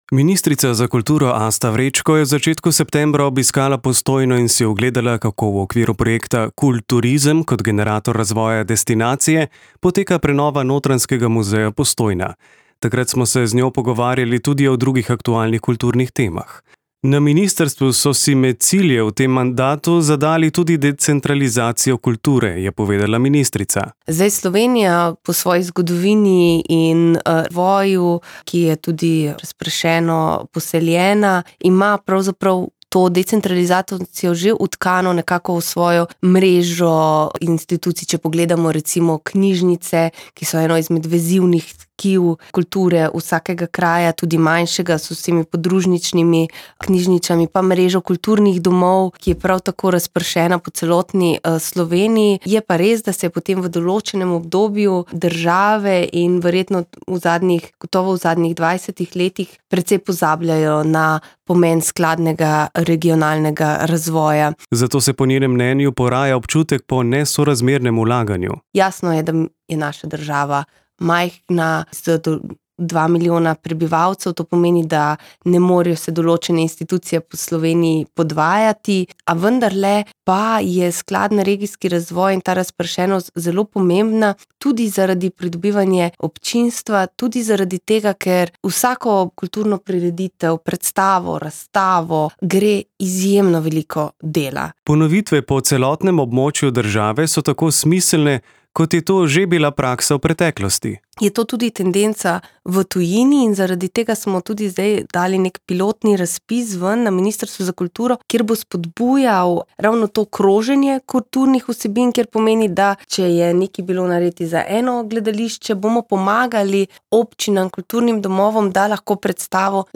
Z ministrico za kulturo Asto Vrečko smo se ob njenem septembrskem obisku Postojne pogovarjali o decentralizaciji kulture, saj je ta med cilji njenega mandata. S pozivom želijo sofinancirati gostovanje kulturnih projektov z različnih področij. Ponovitve po celotnem območju države so smiselne, to je tudi bila praksa v preteklosti, meni ministrica.